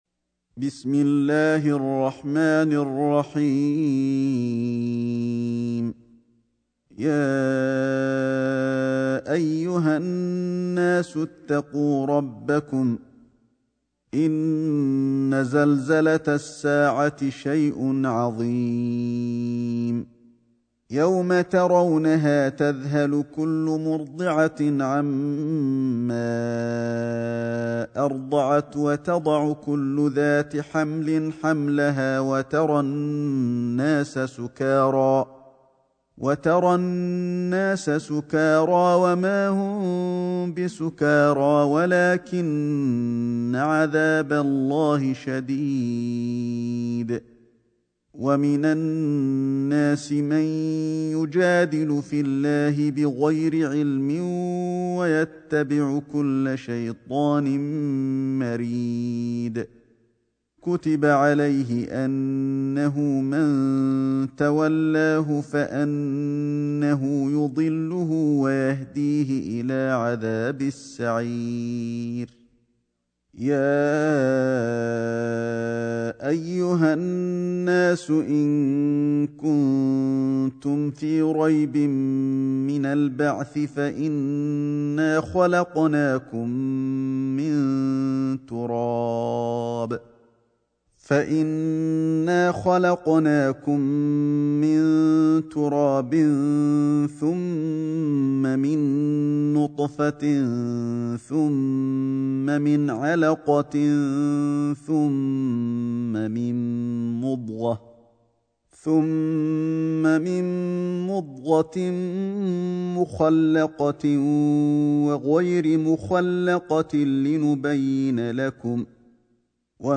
سورة الحج > مصحف الشيخ علي الحذيفي ( رواية شعبة عن عاصم ) > المصحف - تلاوات الحرمين